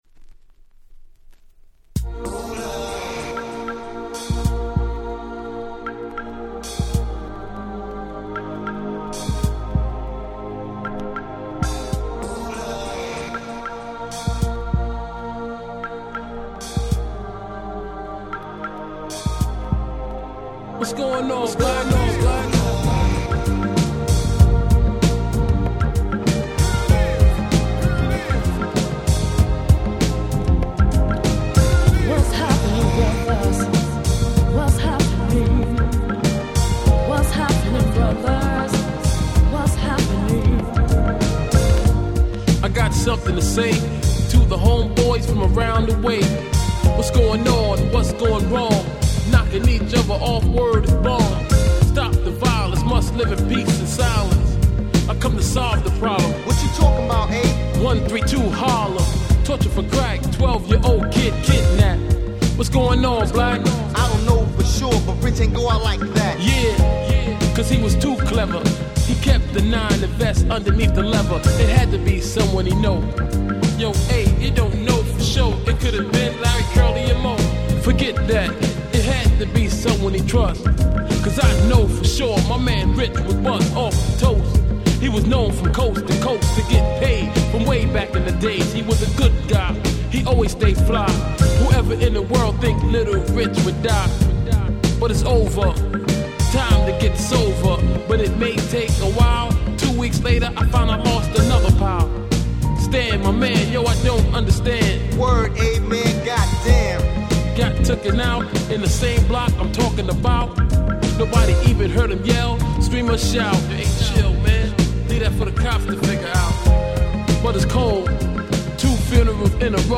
91' Nice Hip Hop !!